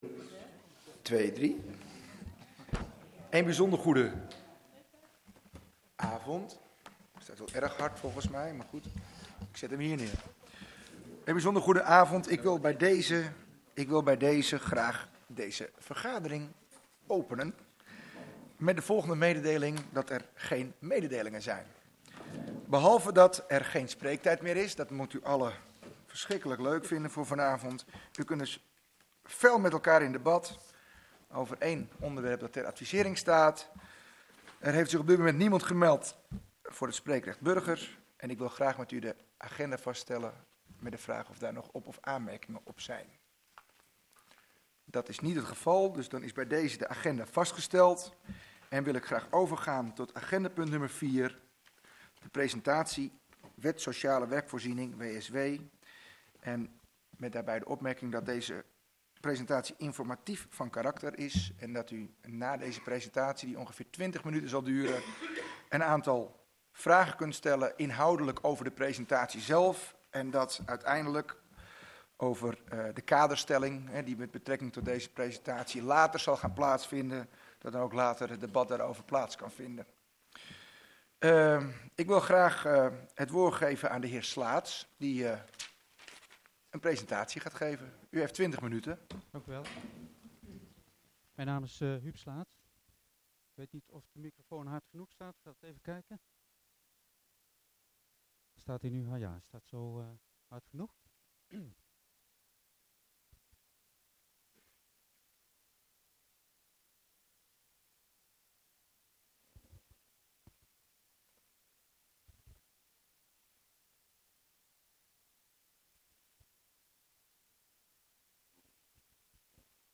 Download de volledige audio van deze vergadering
Locatie: Raadzaal